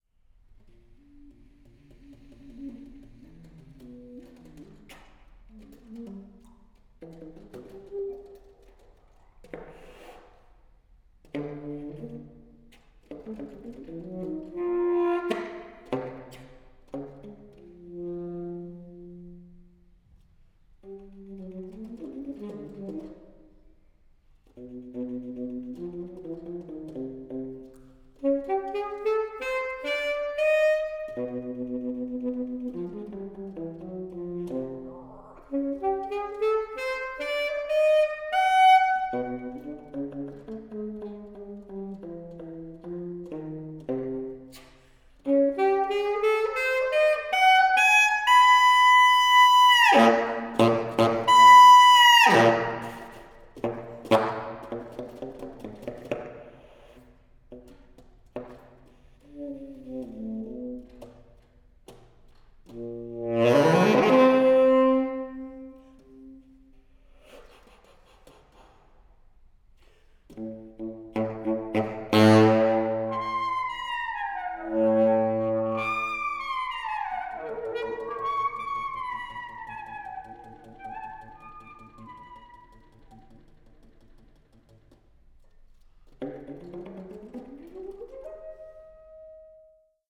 tenor saxophone